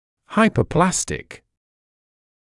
[ˌhaɪpəu’plæstɪk][ˌхайпоу’плэстик]гипопластический, недоразвитый